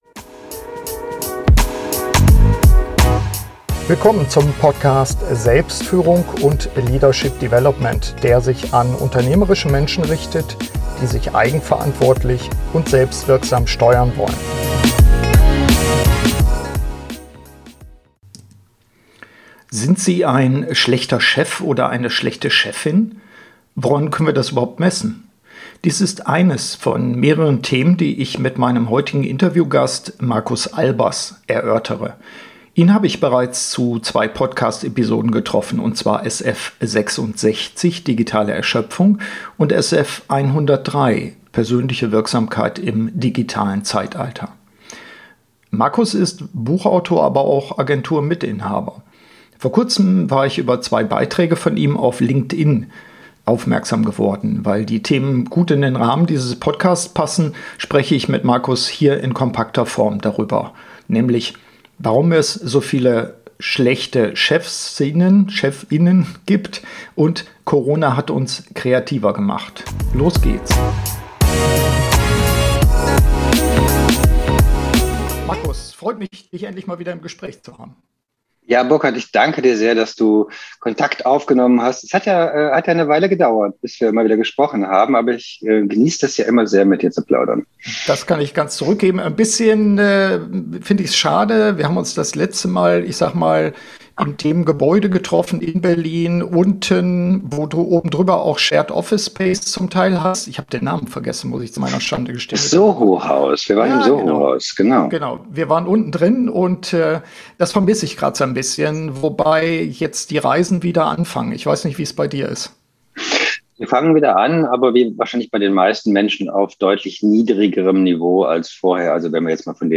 Im Gespräch erörtern wir, was wir in der Krise gelernt haben und was wir in der Zukunft anders machen sollten.